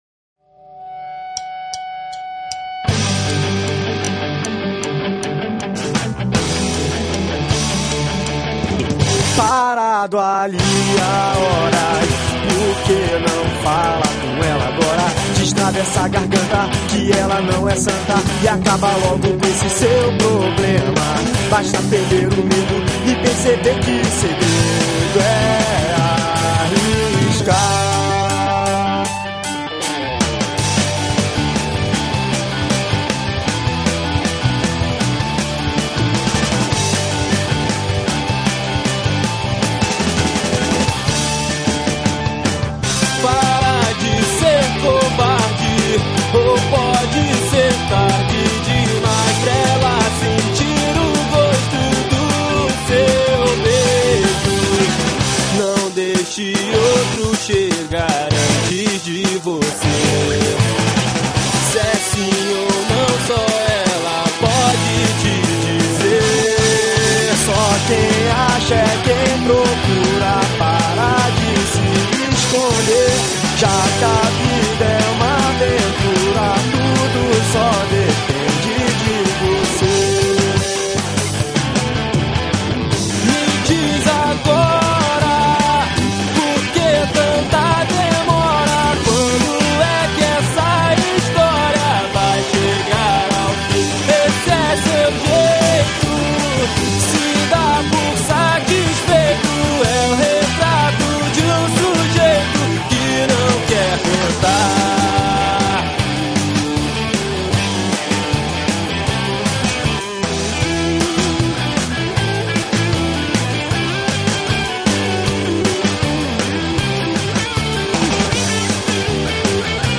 EstiloSurf Music